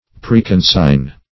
Search Result for " preconsign" : The Collaborative International Dictionary of English v.0.48: Preconsign \Pre`con*sign"\, v. t. [imp.